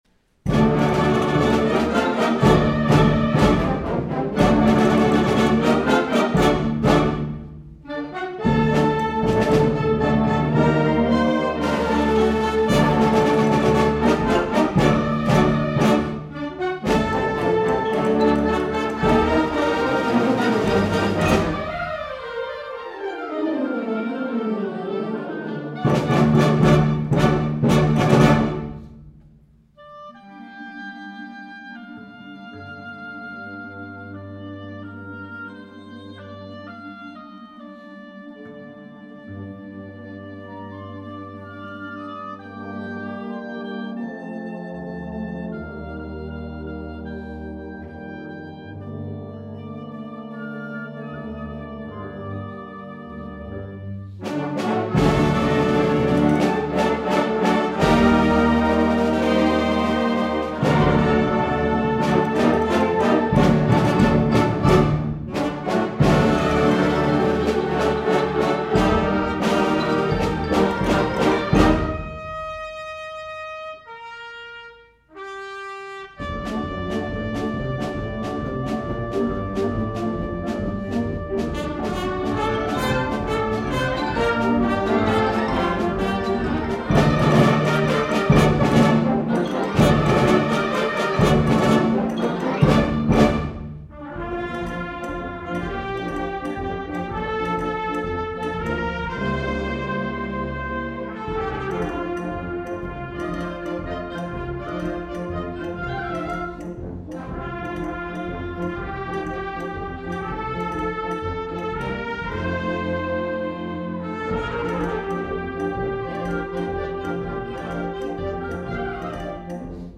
2011 Winter Concert